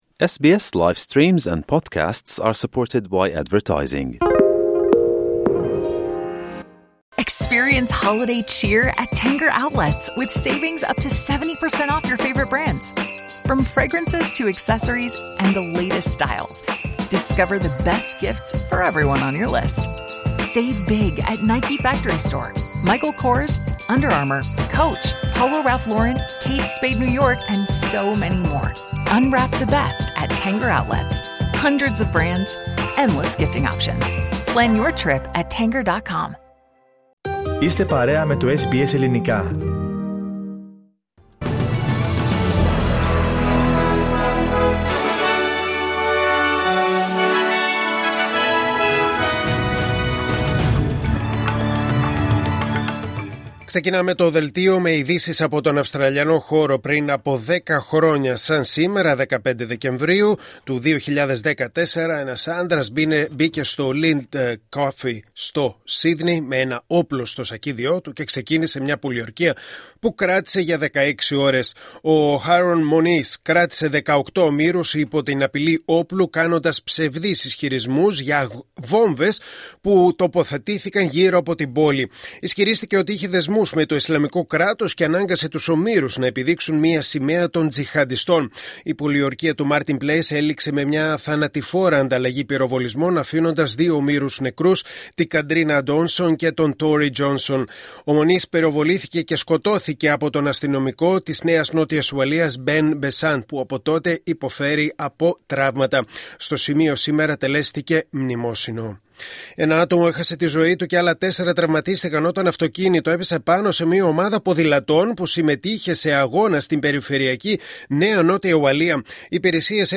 Δελτίο Ειδήσεων Κυριακή 15 Δεκεμβρίου 2024